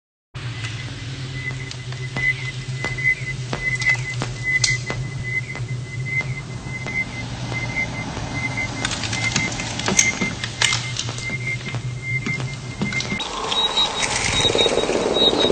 aliens-sonar-ping_24884.mp3